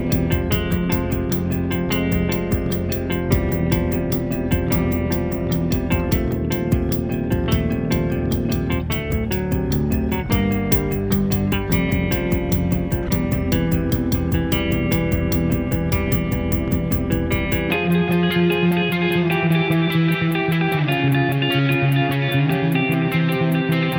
no Backing Vocals Indie / Alternative 3:05 Buy £1.50